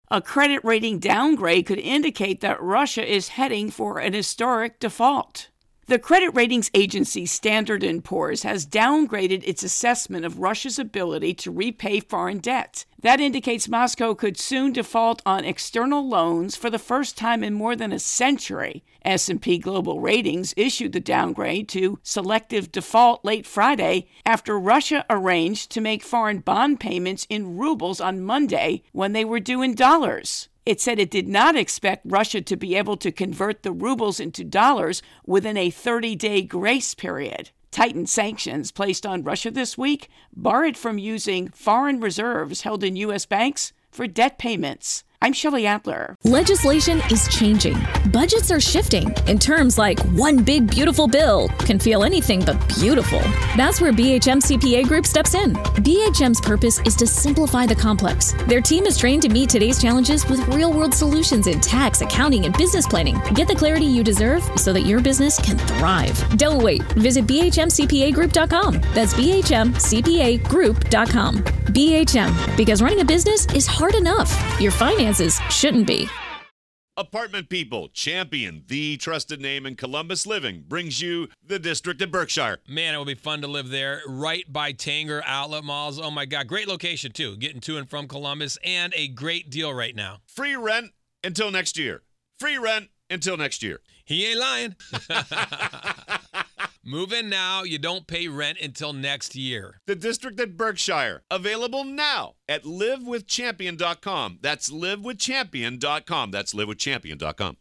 Debt intro and voicer